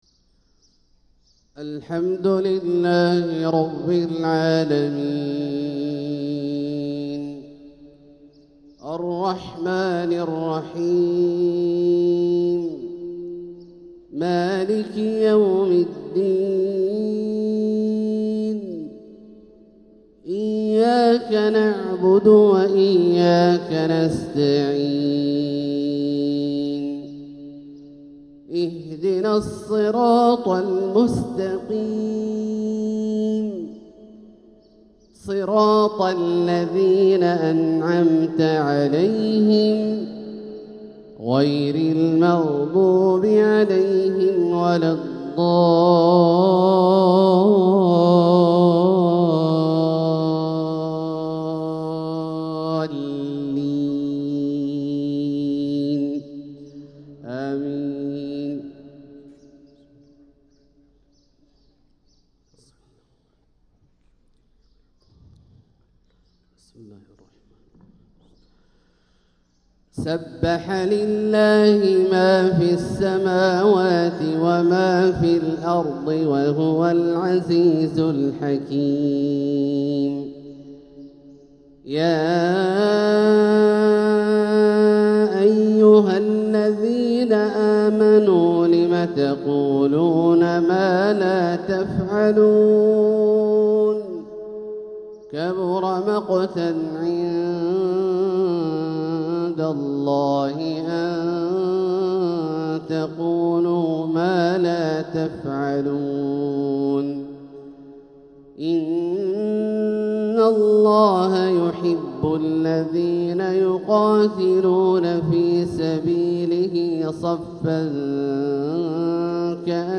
فجر الأحد 5-7-1446هـ سورة الصف كاملة | Fajr prayer from surah as-Saff 5-1-2025 🎙 > 1446 🕋 > الفروض - تلاوات الحرمين